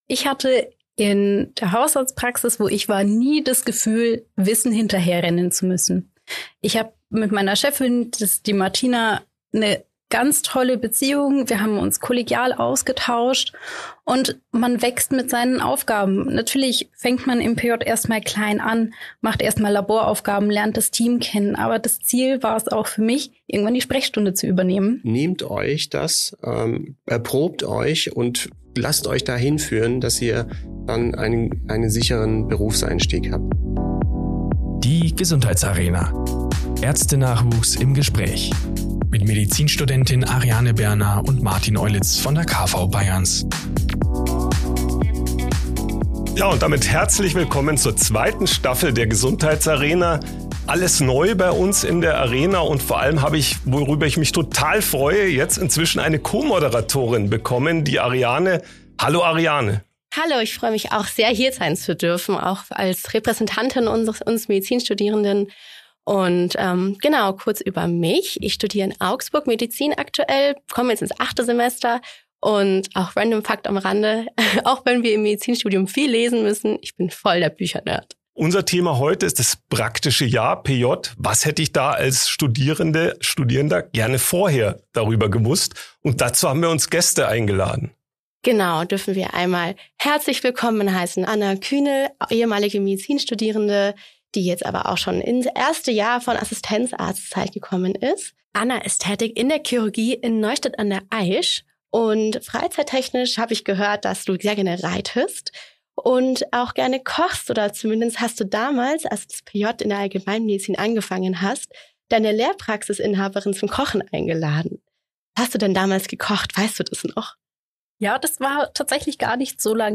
spricht mit seinen Gästen darüber, wie du dich optimal auf das PJ vorbereitest, welche Strukturen dich erwarten und welche Erfahrungen dir den Einstieg erleichtern.